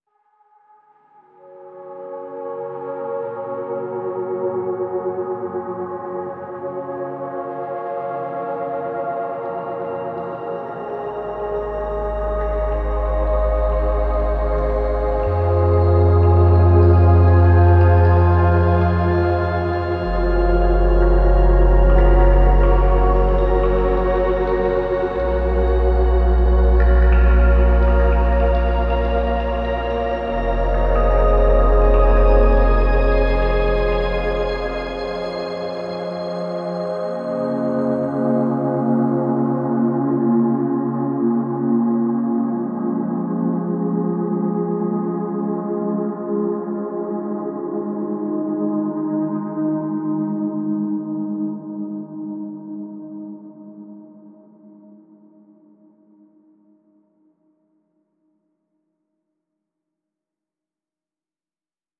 • Жанр: Электро